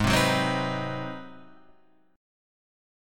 G#7#9 chord